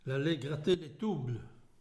Saint-Christophe-du-Ligneron
Catégorie Locution